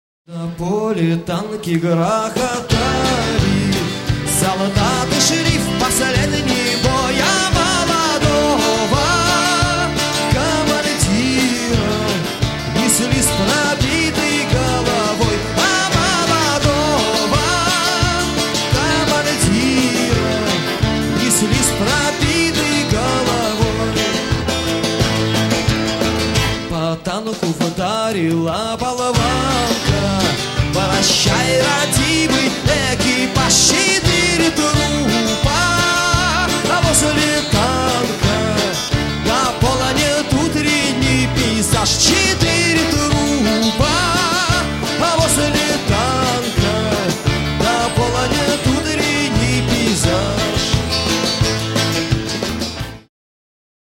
Шаболовка (1996)
AUDIO, stereo